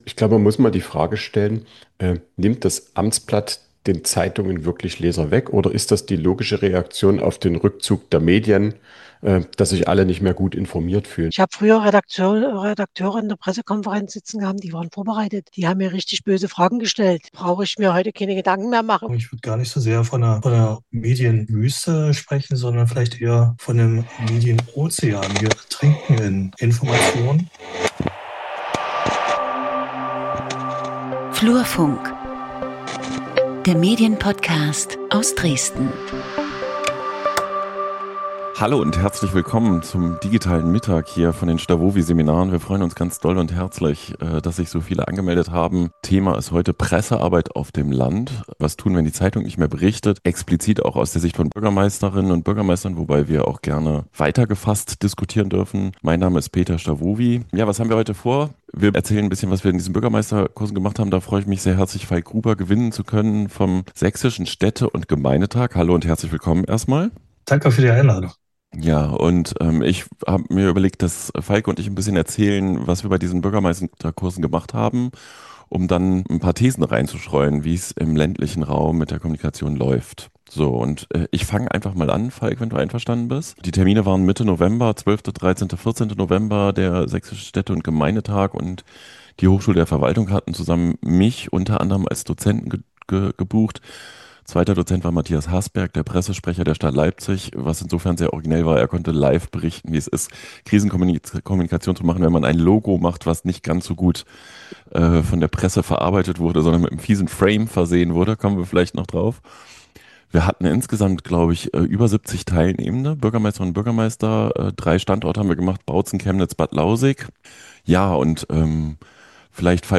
Aufzeichnung des Digitalen Mittag vom 1.12.2025 Wie verändert sich lokale Kommunikation, wenn die Tageszeitung vor Ort verschwindet?